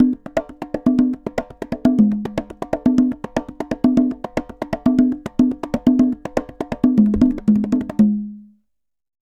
Congas_Baion 120_2.wav